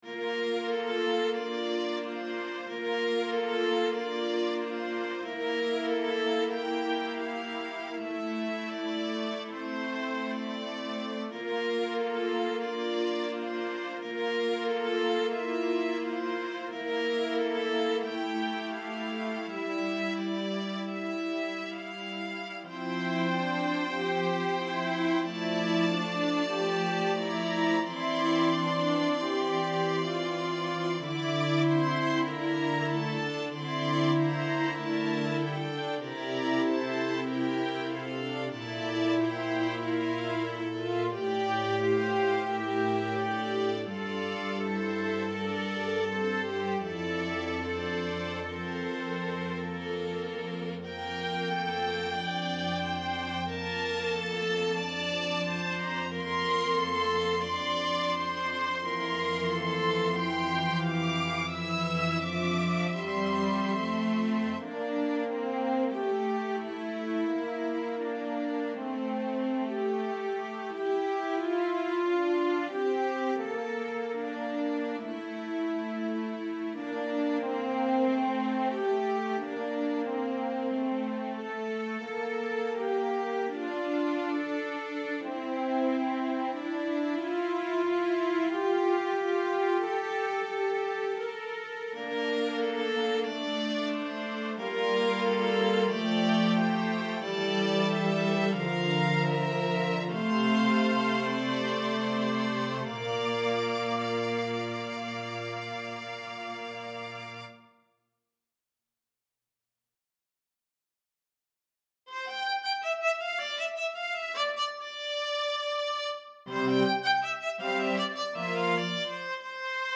(1986) String Quintet